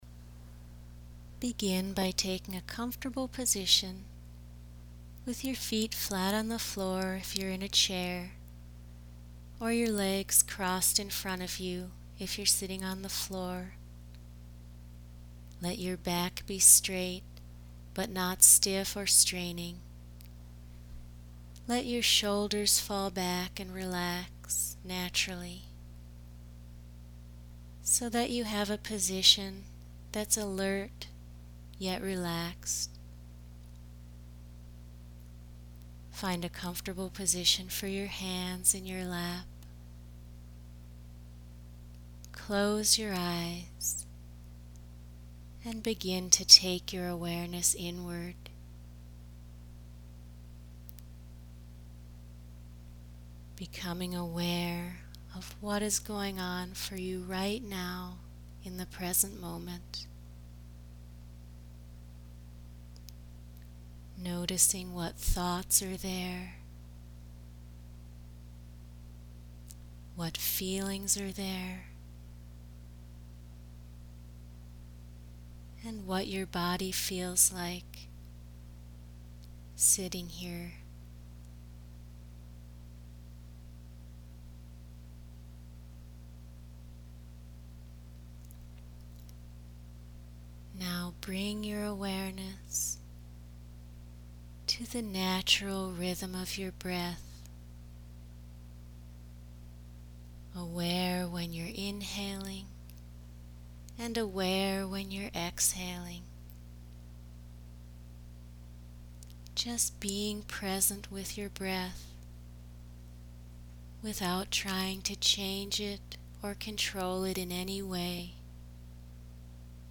Body Awareness Meditations
03 Mindfulness Meditation short.mp3